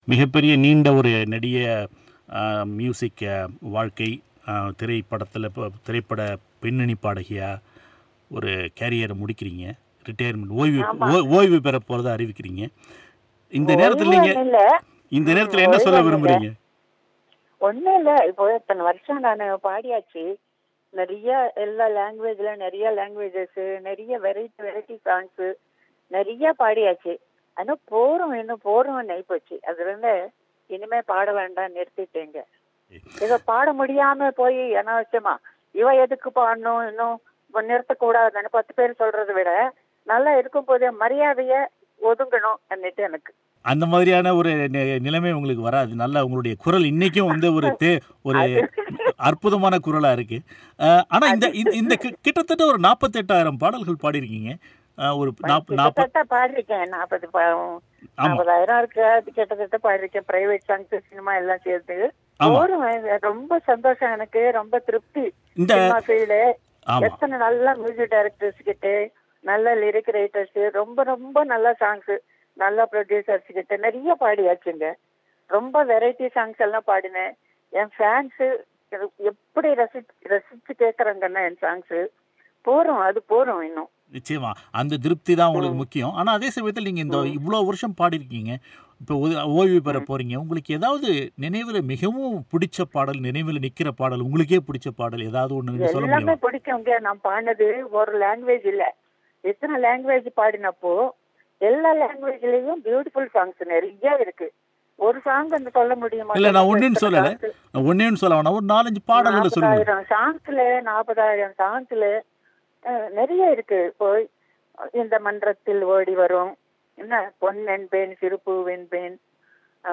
பிபிசி தமிழோசைக்கு அவர் அளித்த பிரத்யேக பேட்டியின் ஒலி வடிவம்.